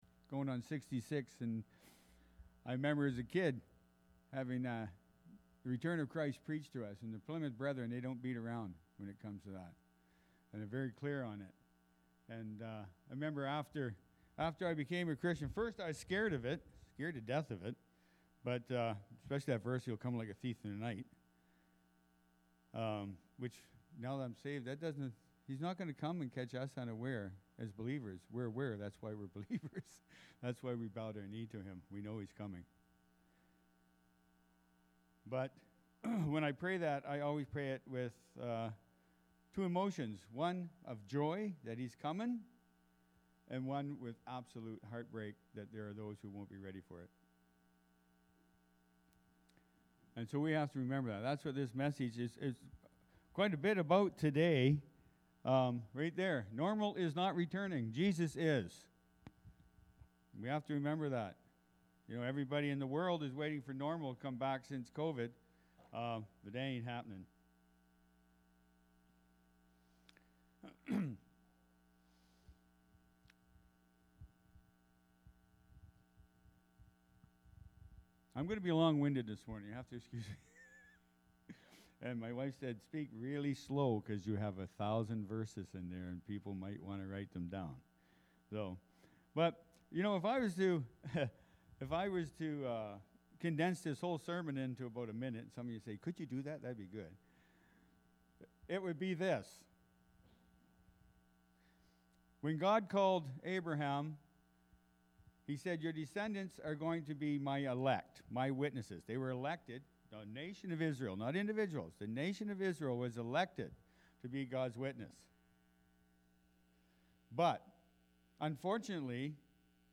People-Get-Ready-Sermon.mp3